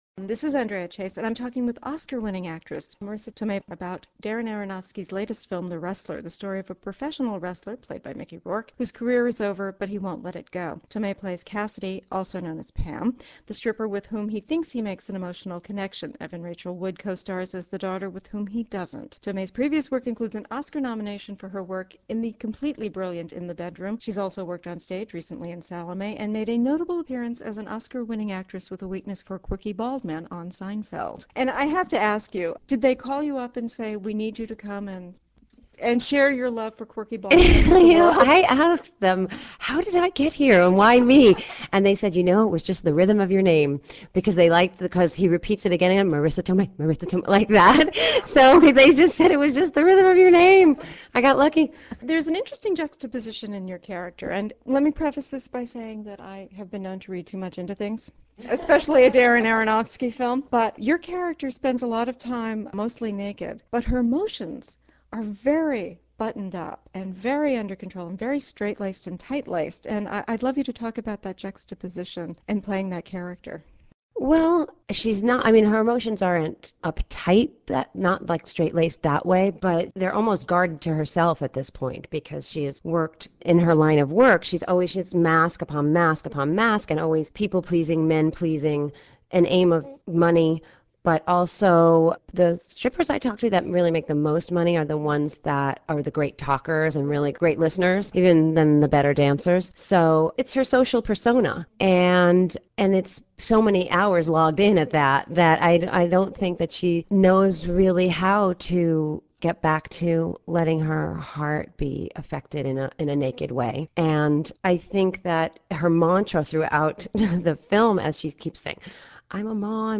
Funny and warm, with a great, bubbly laugh, she was gracious when I did the one thing I had hoped not to do on my drive over to the interview. That would be mispronouncing her name.